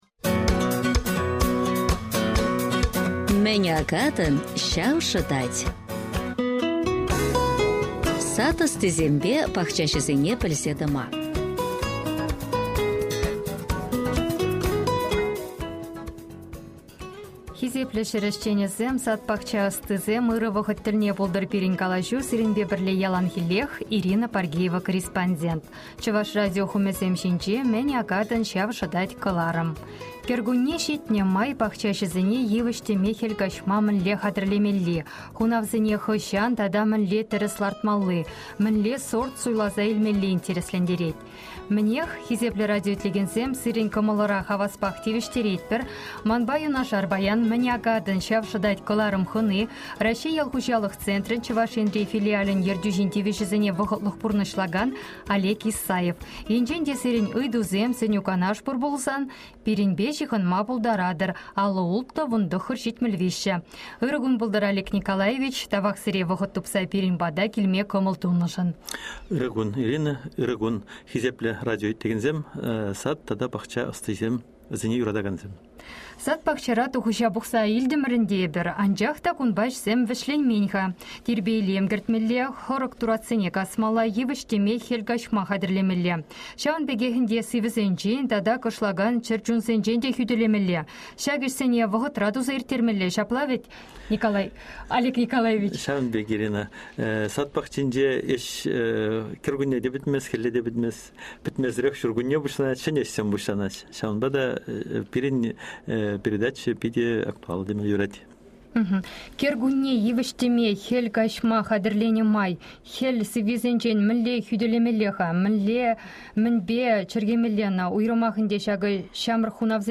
Выступление